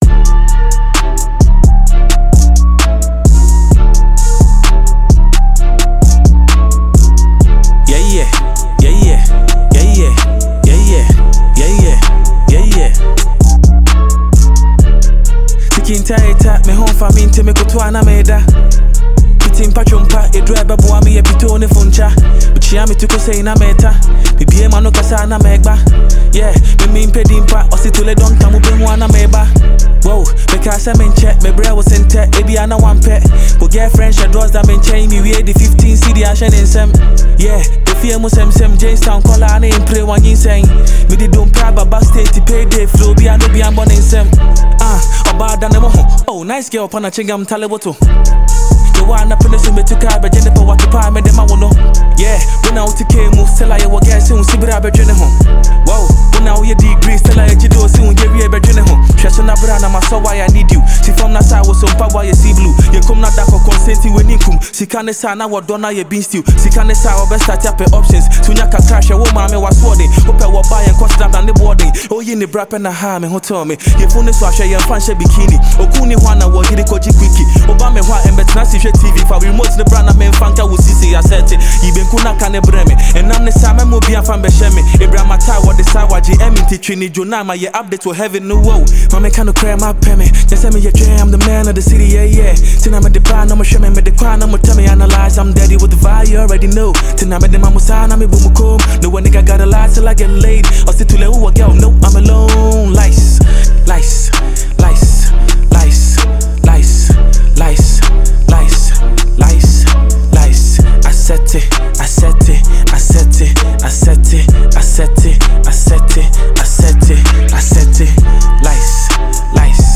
Ghana Music Music